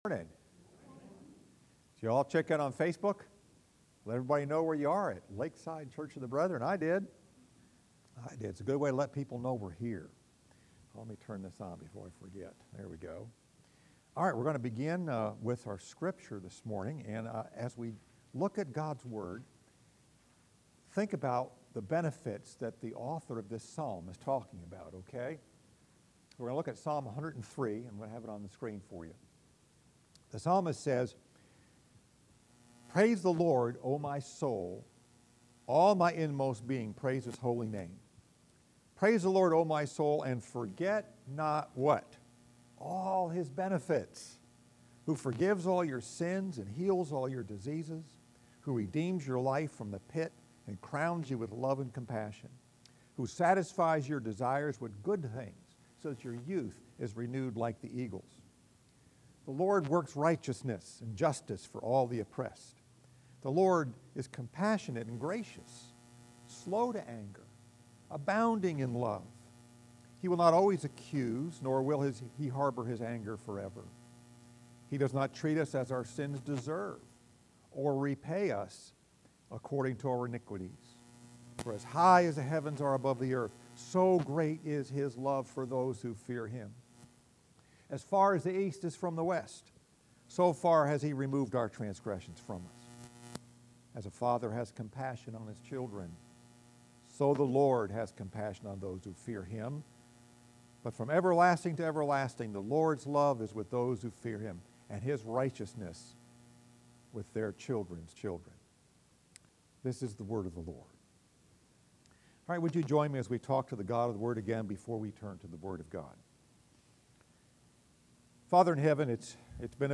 Message: Fringe Benefits Scripture: Psalm 103:1-13